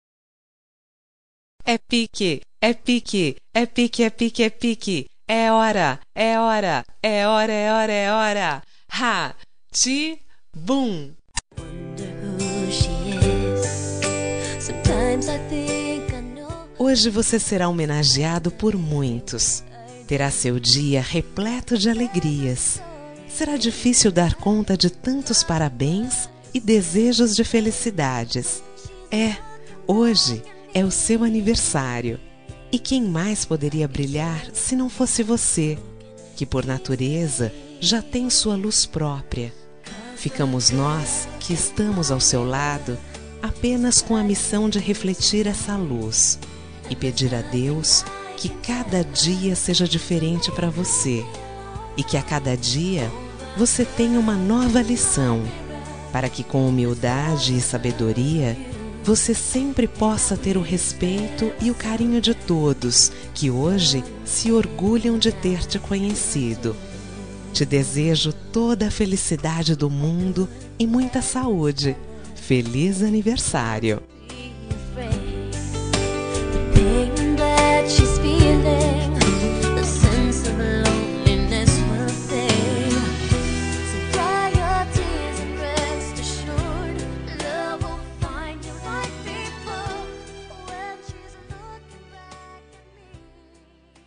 Aniversário de Pessoa Especial – Voz Feminina – Cód: 1895 – Parabéns